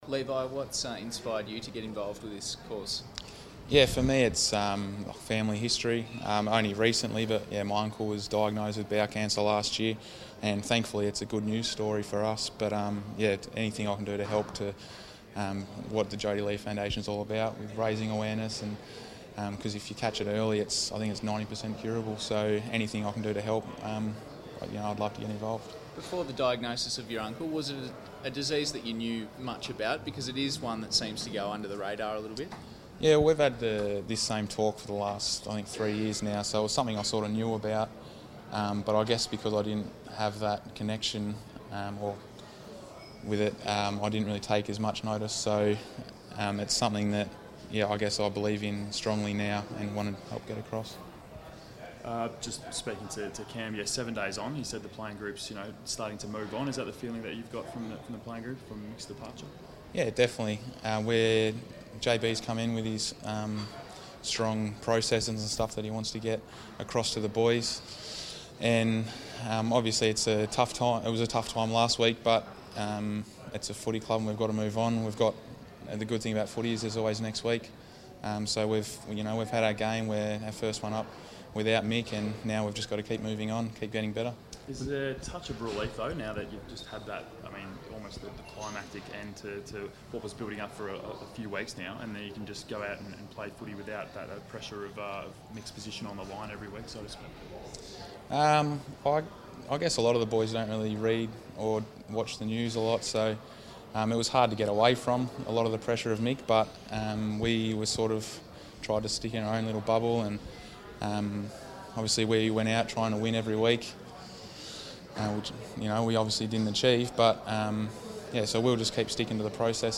Levi Casboult press conference - June 2
Jodi Lee Foundation ambassador Levi Casboult chats to the media at Ikon Park ahead of Carlton's Round 10 clash against Adelaide at the MCG.